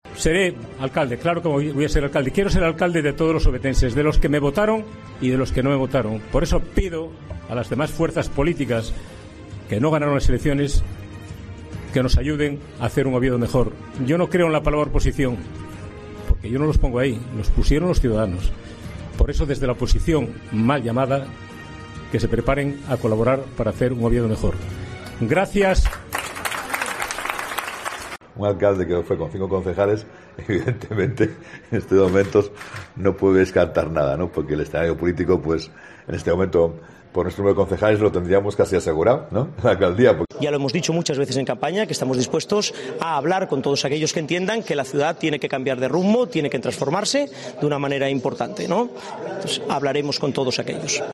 Los candidatos en Oviedo de PP, PSOE y Ciudadanos hablan tras las elecciones